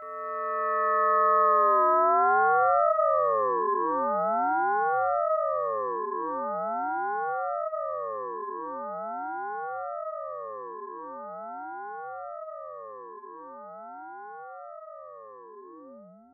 cortina musical